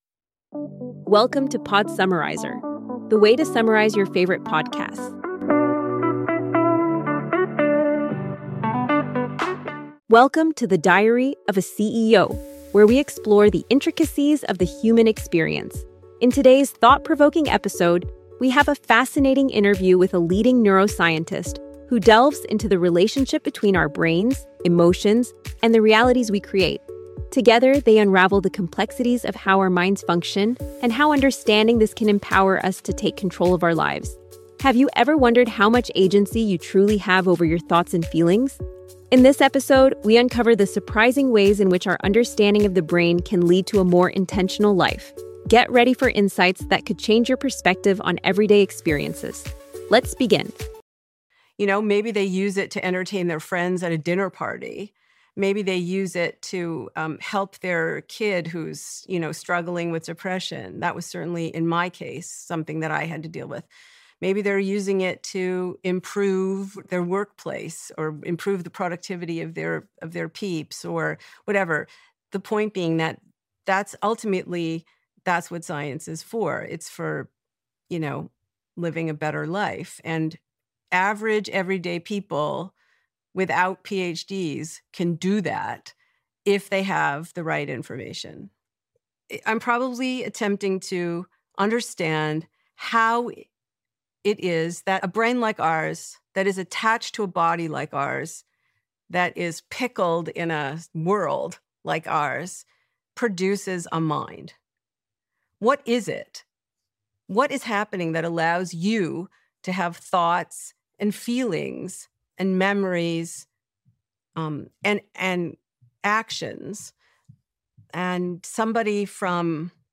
In this insightful podcast episode, the host engages with a neuroscientist to explore the intricate relationship between the brain, emotions, and personal agency.